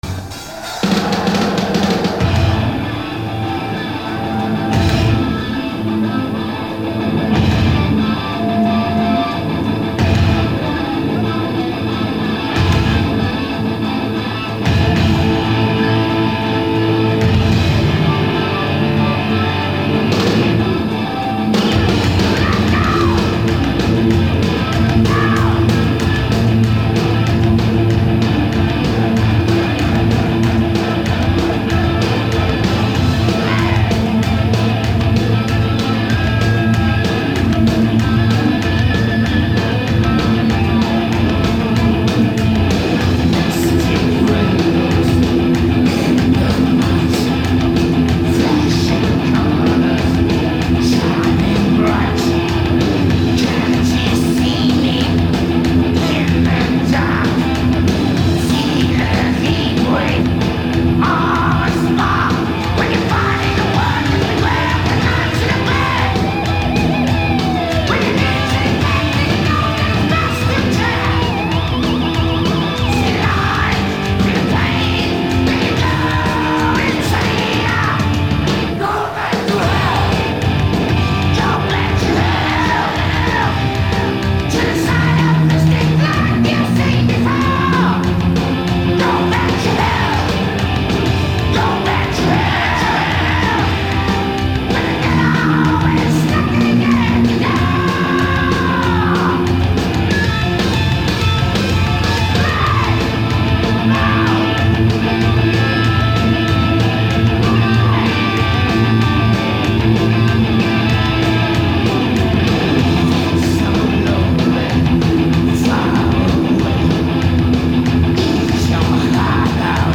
Live Bocum '88